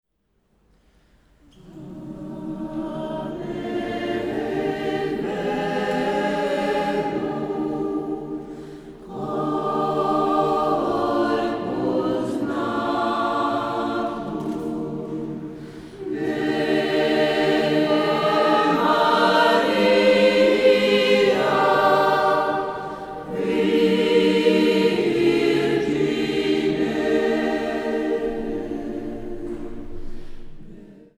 Kören är mp3 från DSD och gitarren inspelad i PCM 16/88.2 så för att kunna spela den senare måste du uppenbarligen antingen ha ett ljudkort med 88.2kS/s eller så får du importera filerna in i lämpligt program och konvertera ner till lägre sampelhasighet.
Signalen är tagen från hörlursutgången som sedan matas in i AD-omvandlarens linjeingång.
Även bruset kändes något annorlunda, kanske lite olika dist/karaktär, men det är svårt att komma ner i bruset och lyssna både därför att inspelningens brus är ganska högt och det faktum att det är så tight klippt i början och slutet.